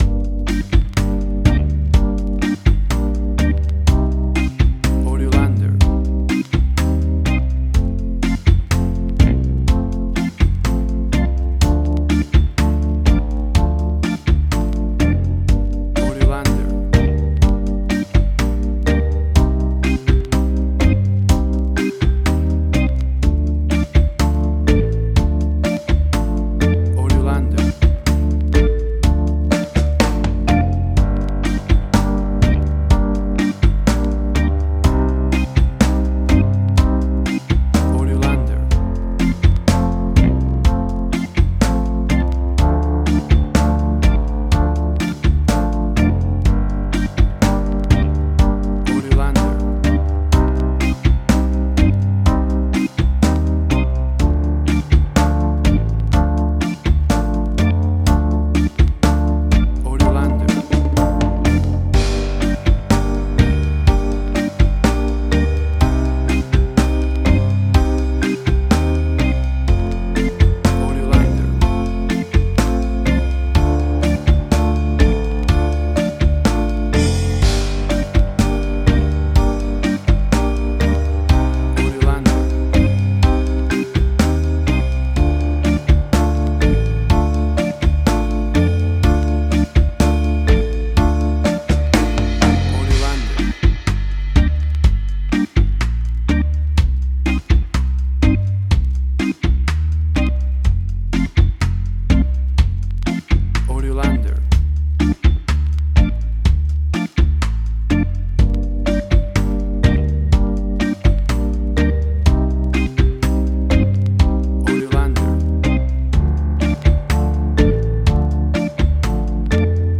Reggae caribbean Dub Roots
Tempo (BPM): 62